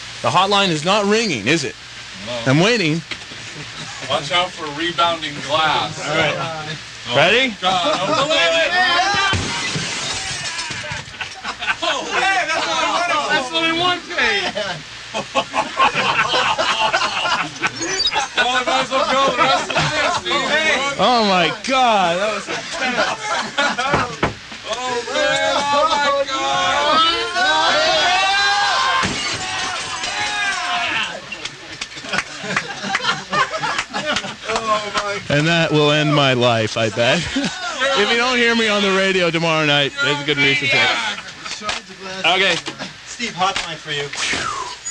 Broken studio window at Live 105 KITS 1991